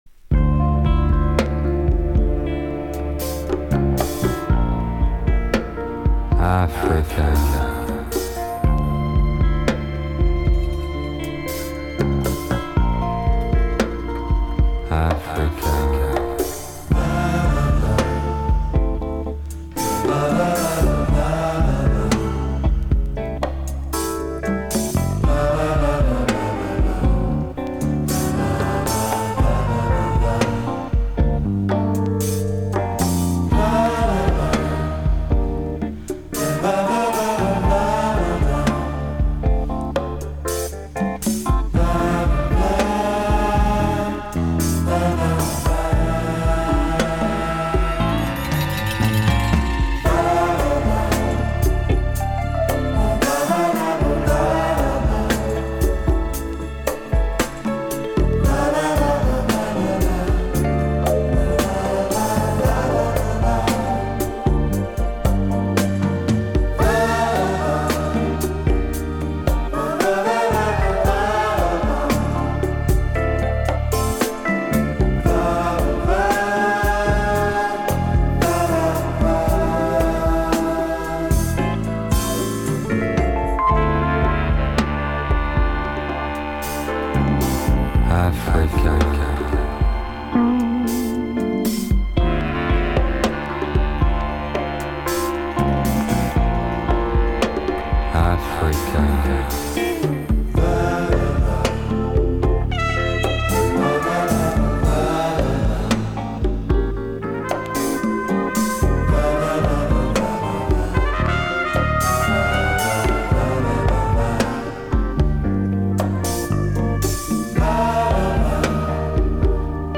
А дальше уже шла знакомая мелодия, труба солировала.